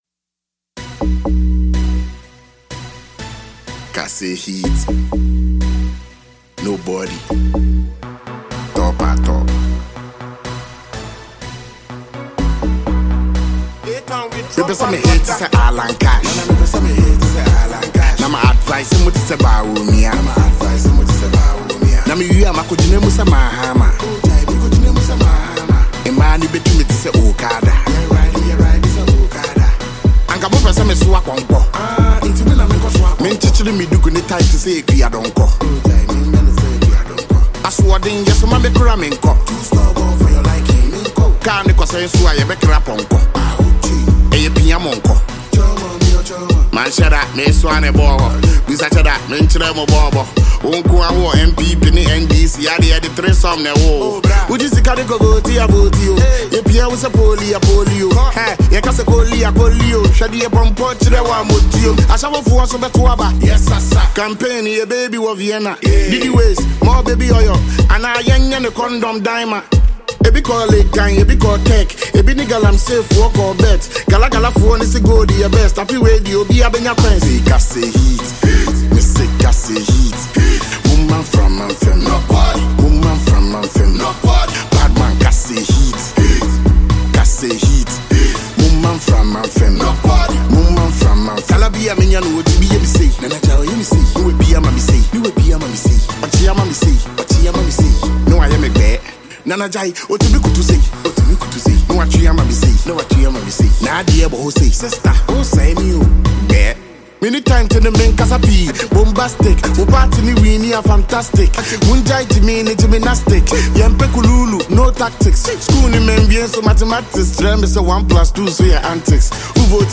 Popular Ghanaian rapper and songwriter